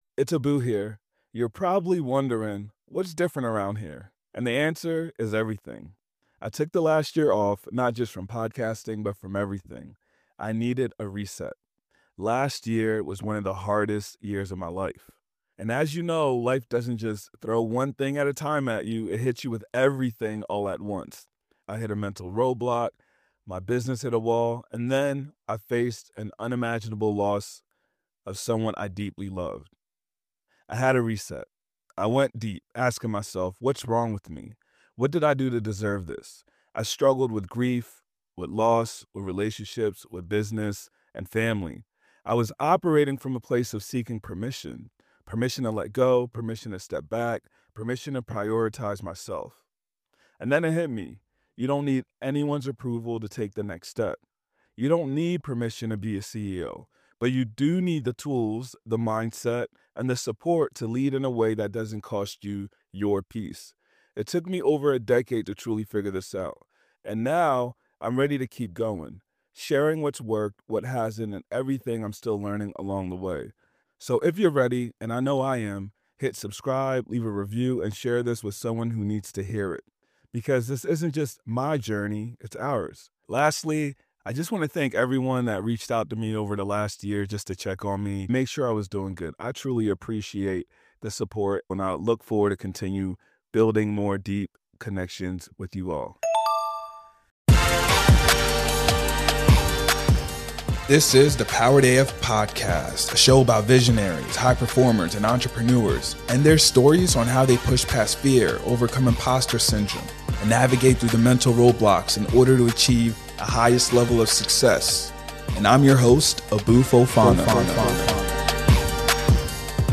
EP 19: [Powered Chat] Facebook Ads, How to Find Your Customers Online,High-Ticket Coaching: Q&A interview